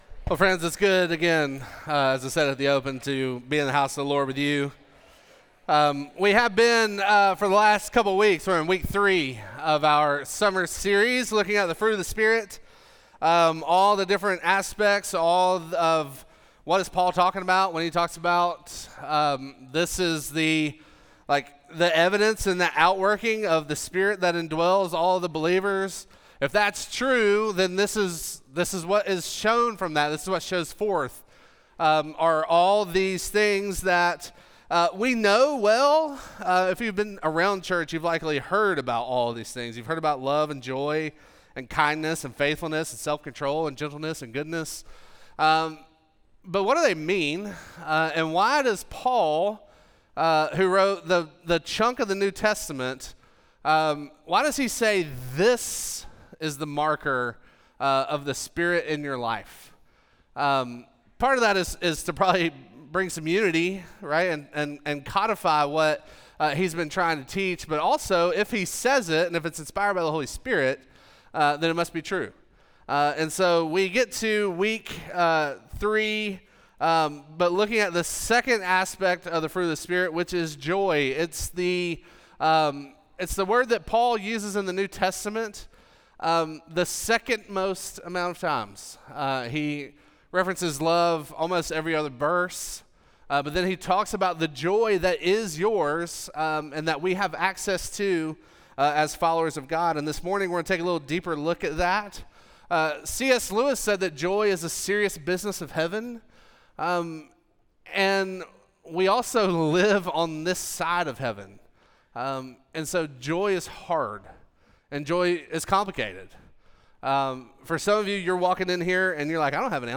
Midtown Fellowship 12 South Sermons Life By The Spirt – Joy – WBE Jun 08 2025 | 00:25:40 1x 00:00 / 00:25:40 Subscribe Share Apple Podcasts Spotify Overcast RSS Feed Share Link Embed